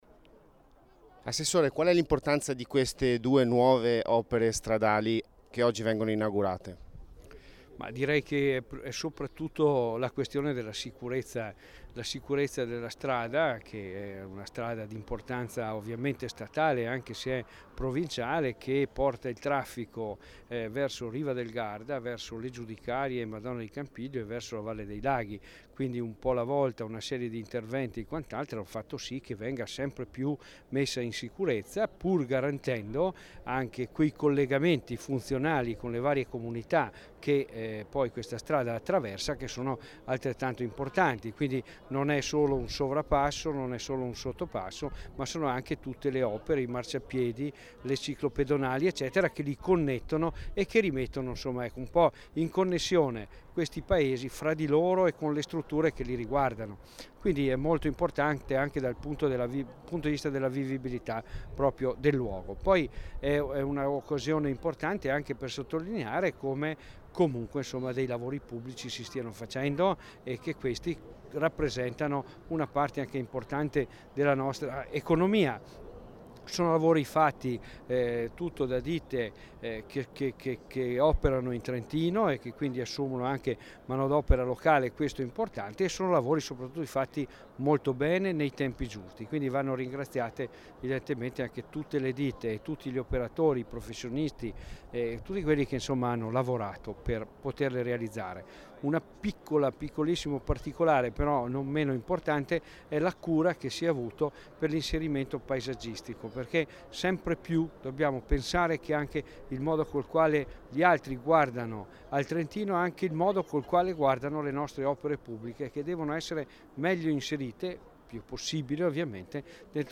int_Gilmozzi_Vezzano_MP3_192K.mp3